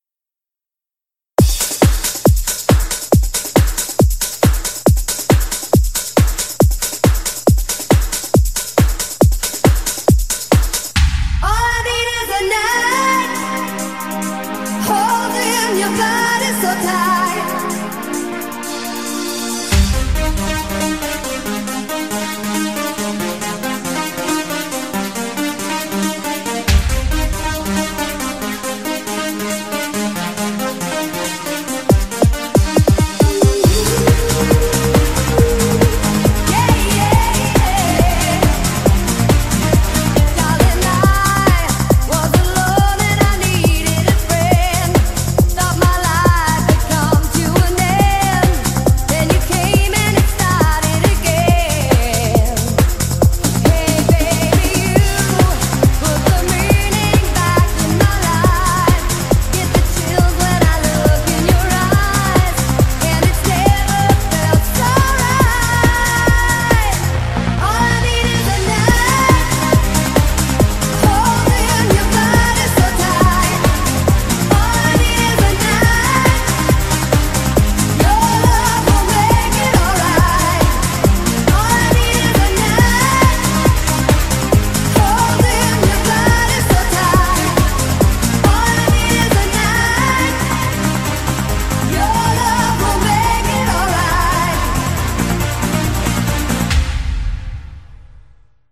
BPM137--1
Audio QualityMusic Cut
- Music from custom cut